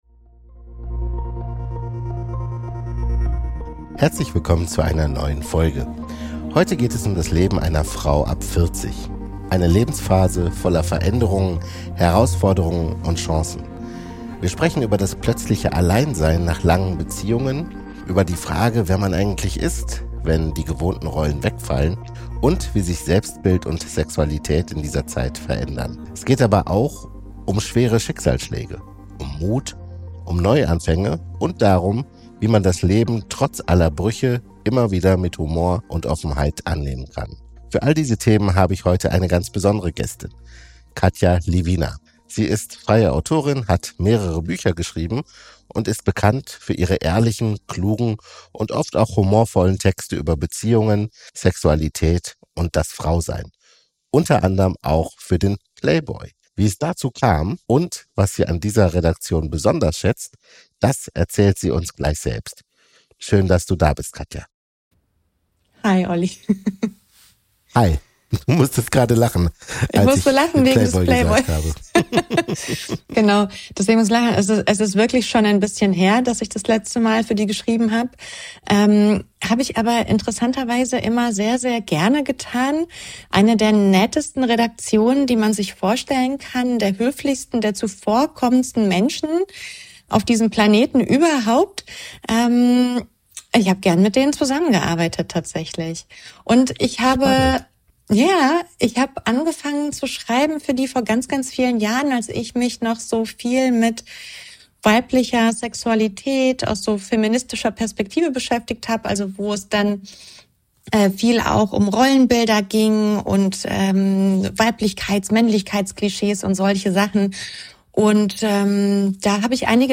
Ein ehrliches Gespräch über Veränderung, Trauer und das Ankommen bei sich selbst.